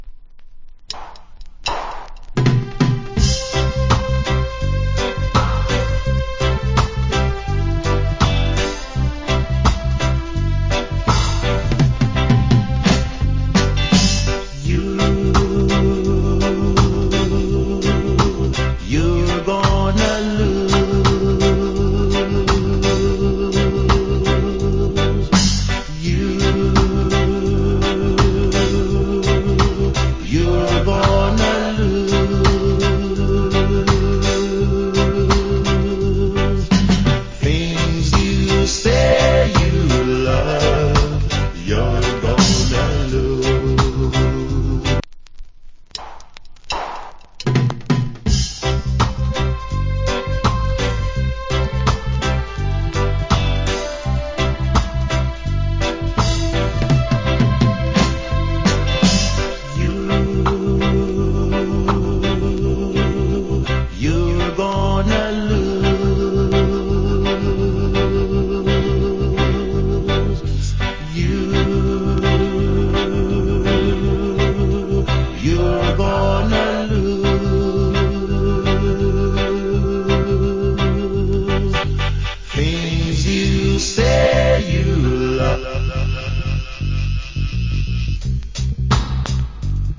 80's. Reggae Vocal.